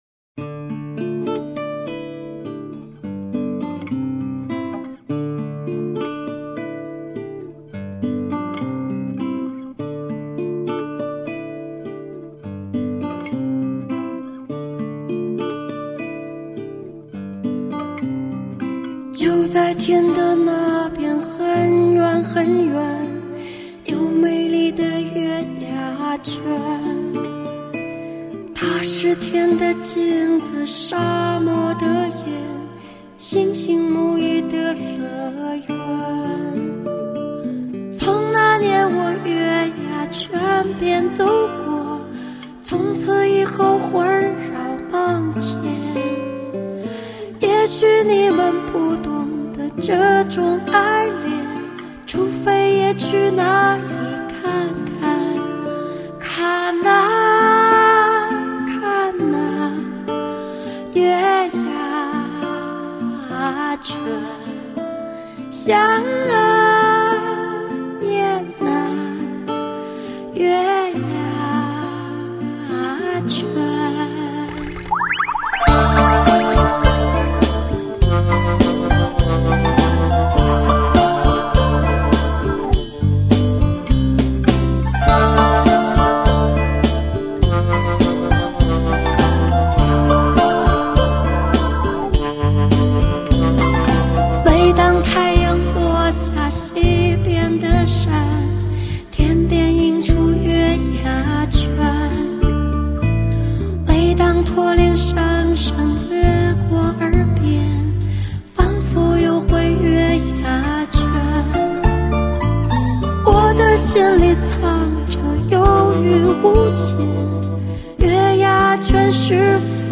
全国流行歌坛“西北风”头号旗手，当今歌坛最独特的声音爆发十足唱腔魅力，深请吟唱现代人内心孤独的苦。
当今歌坛最独特的声音爆发十足唱腔魅力，